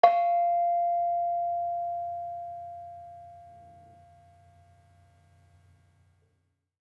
Bonang-F4-f.wav